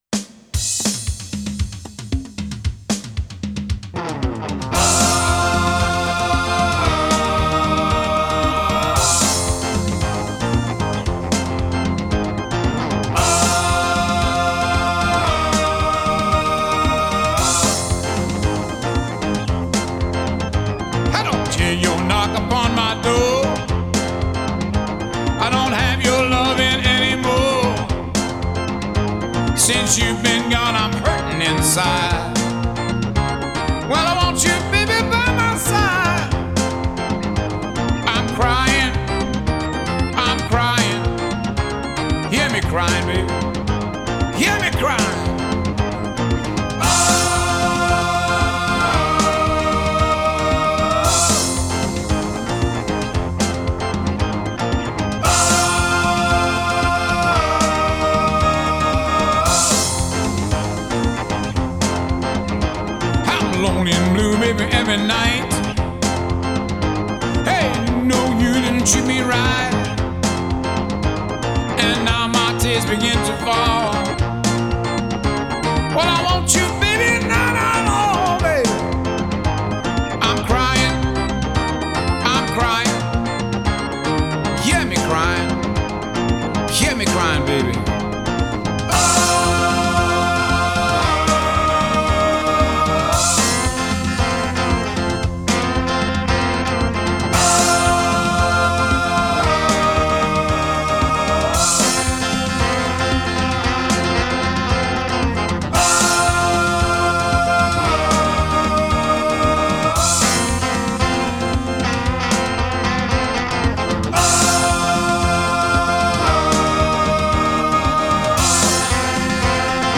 Жанр: психоделический рок, блюз-рок, Ритм-н-блюз, Рок
Genre: Blues, Rock